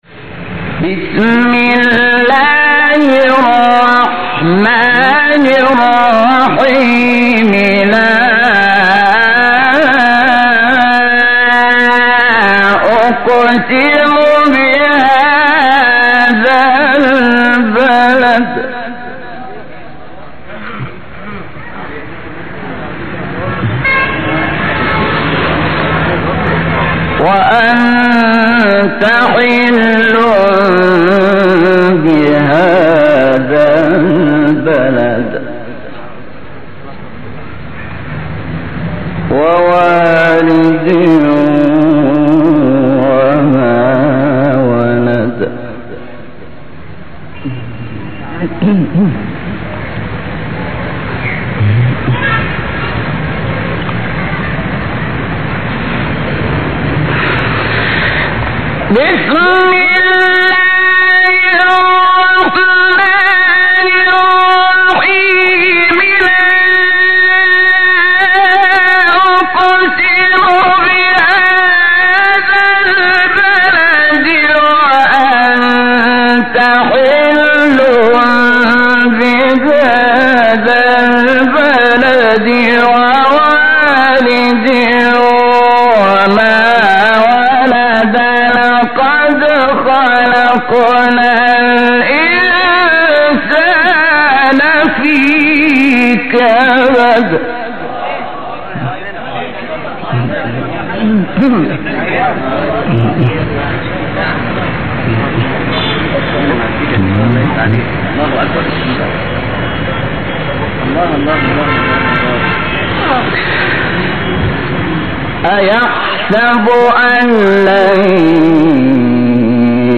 تلاوت آیه 1-12 سوره بلد استاد شحات مقام سه گاه | نغمات قرآن | دانلود تلاوت قرآن